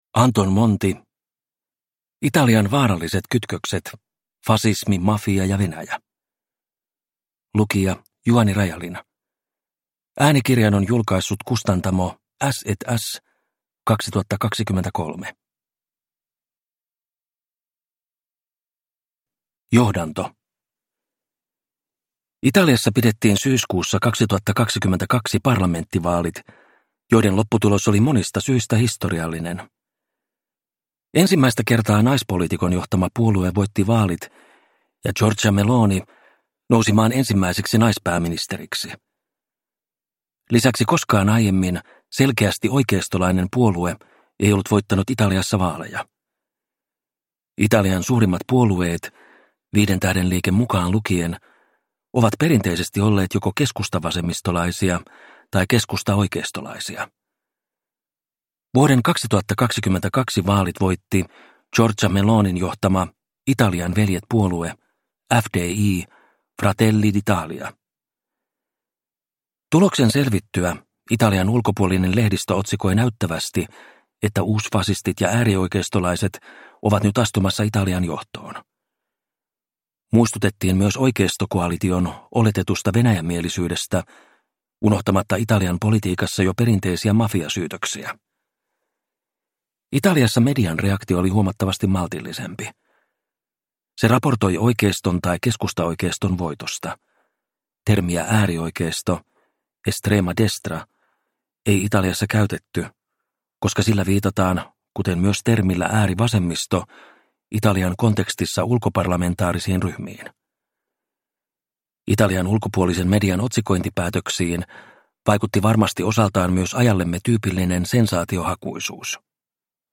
Italian vaaralliset kytkökset - fasismi, mafia ja Venäjä – Ljudbok – Laddas ner